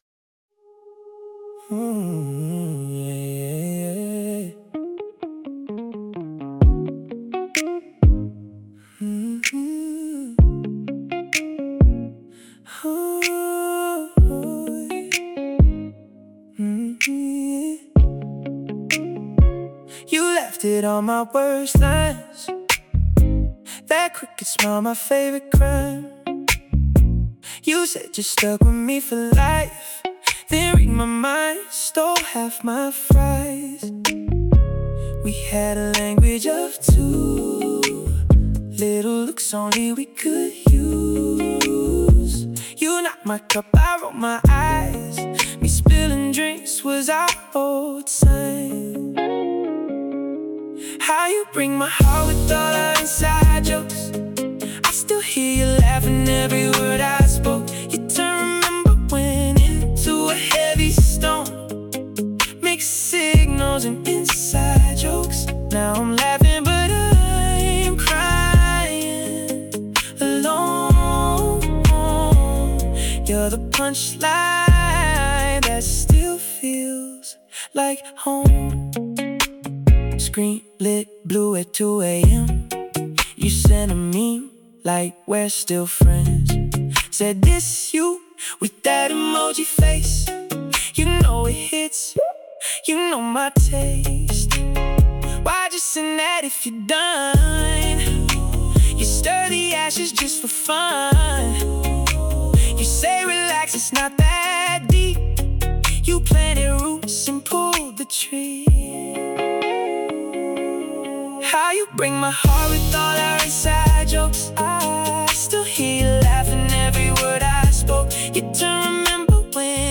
Romantic | Playful 88BPM